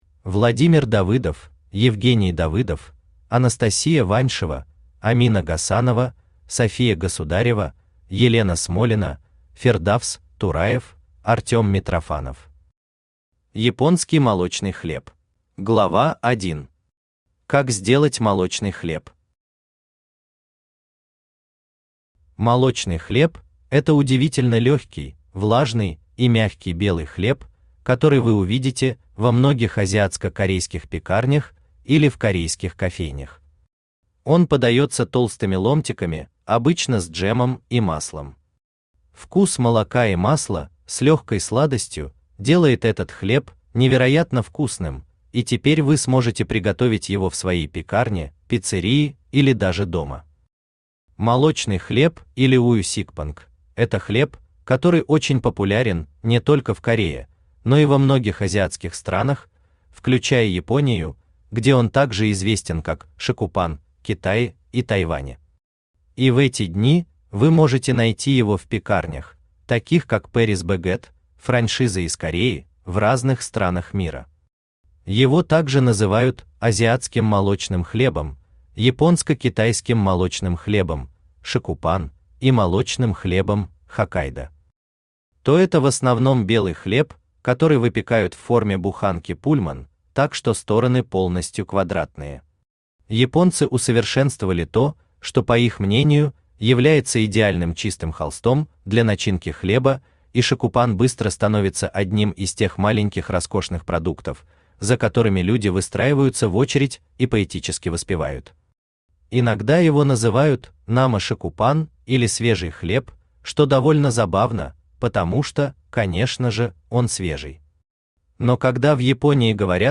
Аудиокнига Японский молочный хлеб | Библиотека аудиокниг
Aудиокнига Японский молочный хлеб Автор Владимир Давыдов Читает аудиокнигу Авточтец ЛитРес.